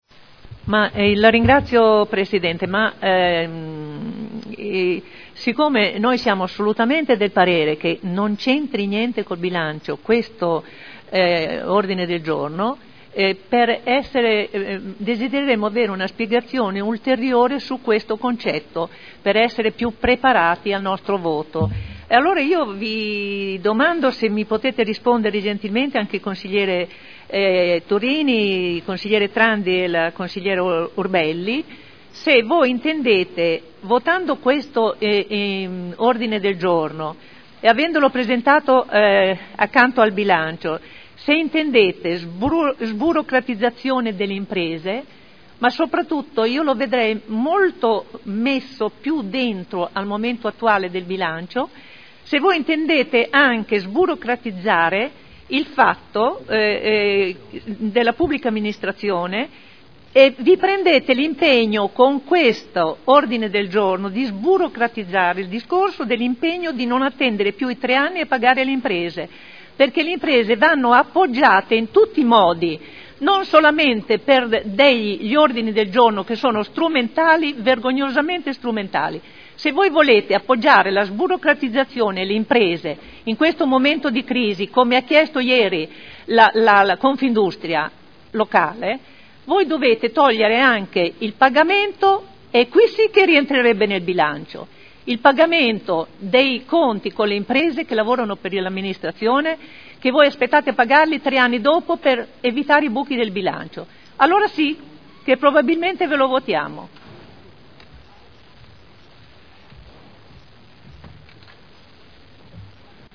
Seduta 28/03/2011. Interviene contro la discussione sull'odg 36350.
Audio Consiglio Comunale